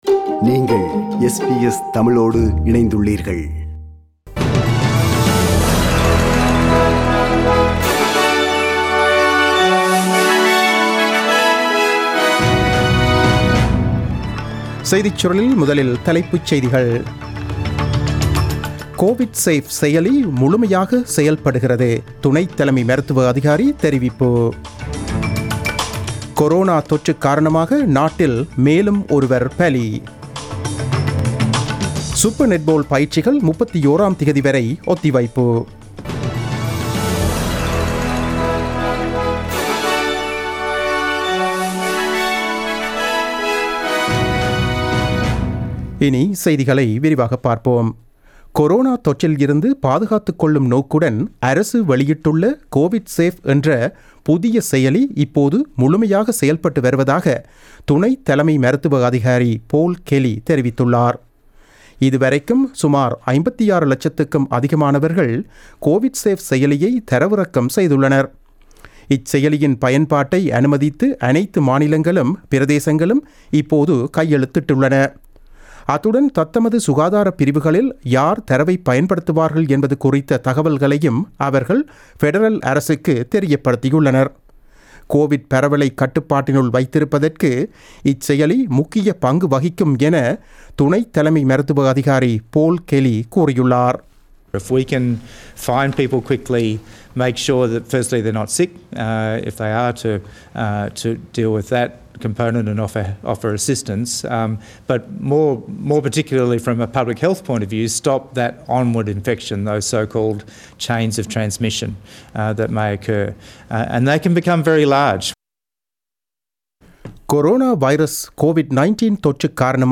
The news bulletin broadcasted on 13 May 2020 at 8pm.